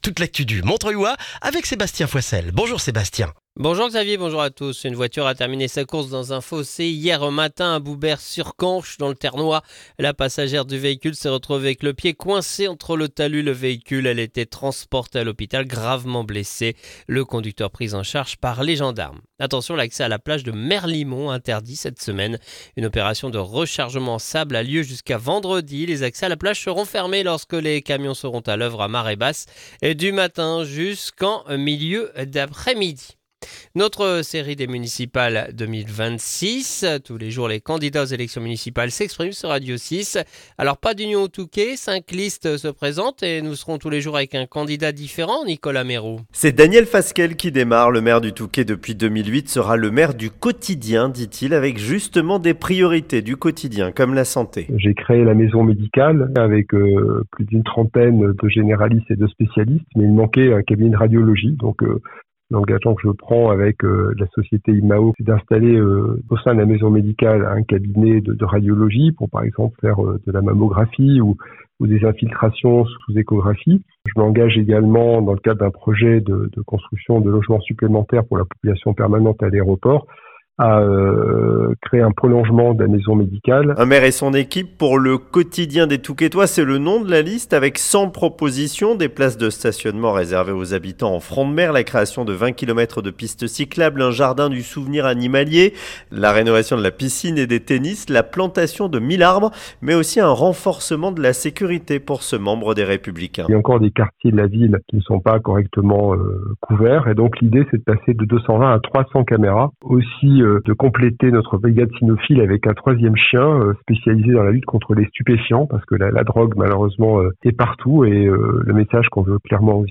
Le journal du lundi 9 mars dans le montreuillois